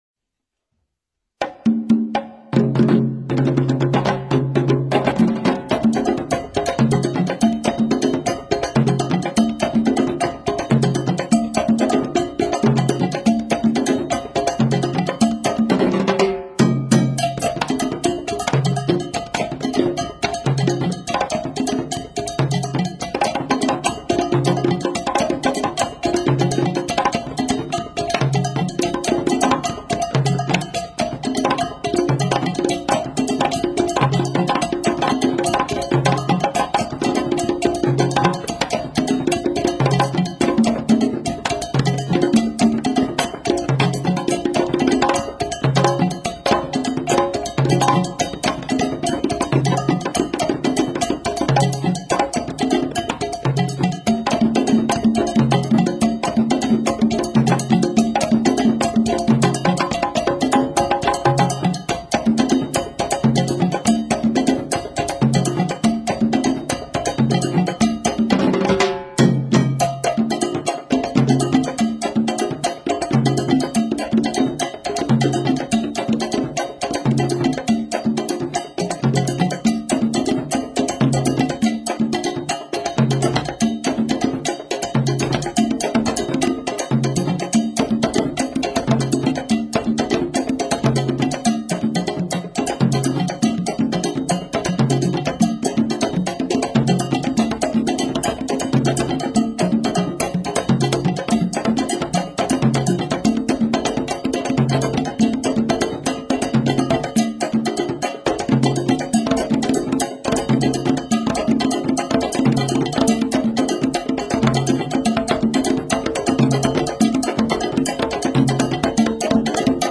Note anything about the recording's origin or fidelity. Percussion at school